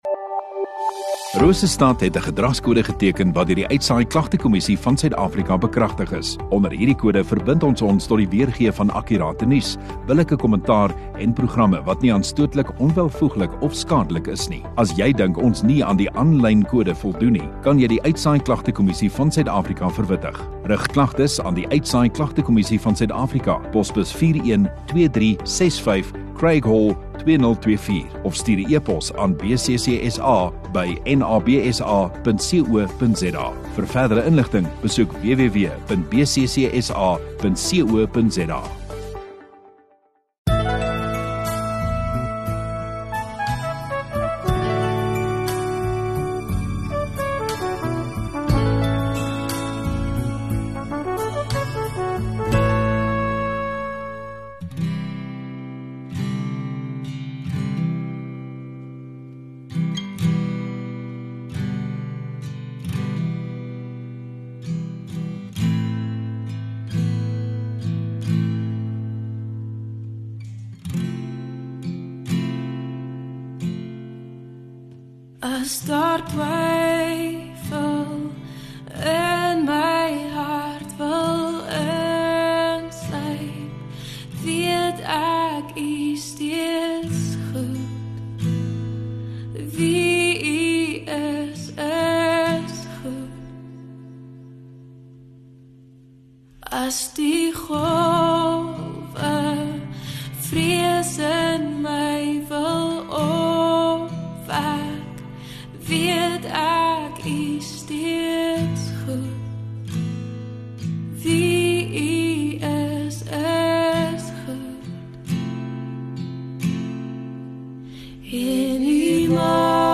5 Jan Sondagaand Erediens